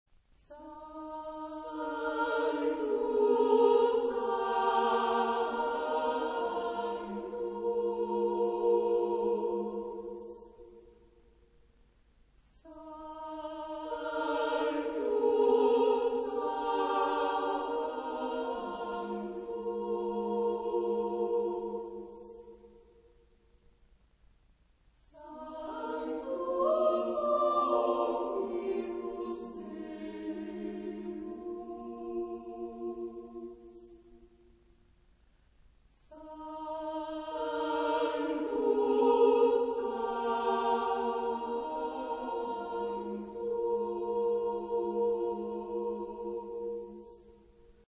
Genre-Stil-Form: geistlich ; Teil einer Messe
Charakter des Stückes: hervorgehoben ; Legato
Chorgattung: SATB  (4 gemischter Chor Stimmen )
Tonart(en): Es lydisch